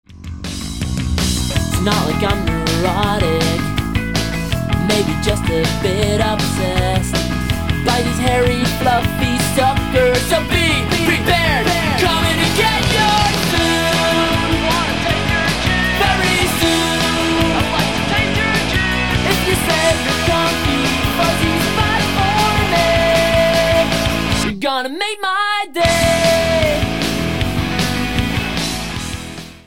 Punkrock op zijn Vlaams.
Punky energie, poppy refreintjes, feest!